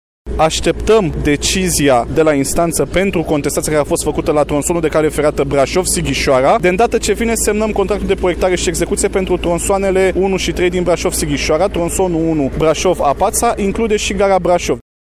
Precizările au fost făcute de Răzvan Cuc, ministrul Transporturilor, aflat, aseară, în vizită la șantierul Autostrăzii, la Râșnov: